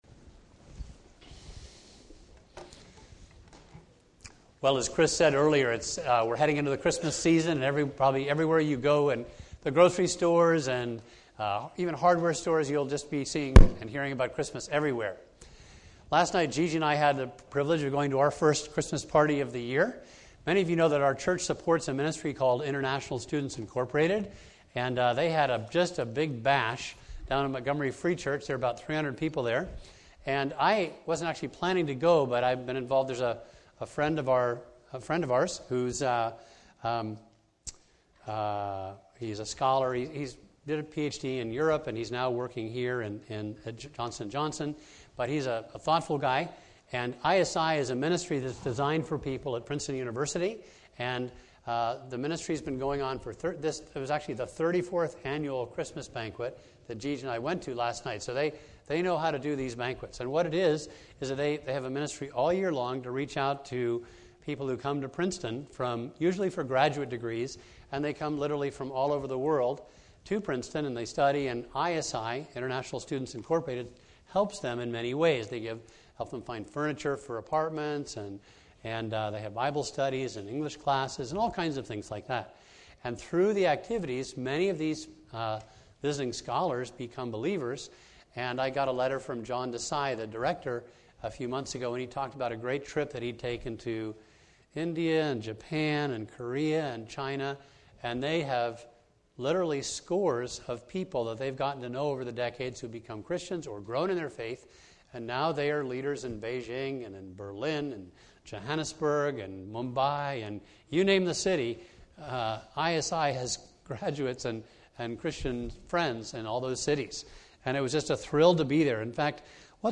A message from the series "Heaven Help The Home."